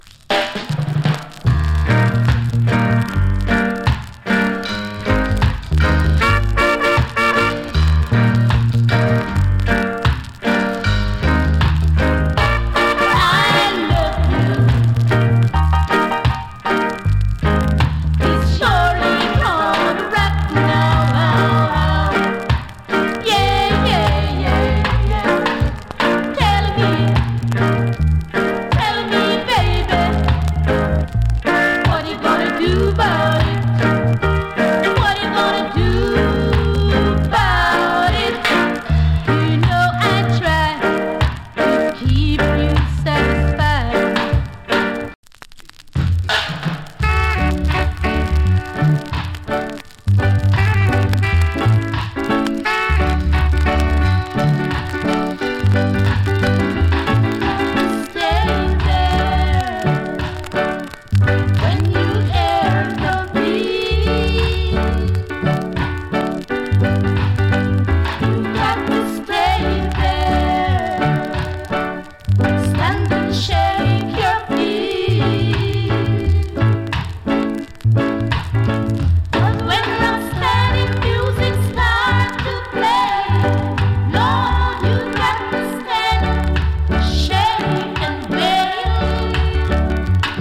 チリ、パチノイズ有り。
VERY RARE FEMALE VOCAL ROCK STEADY !!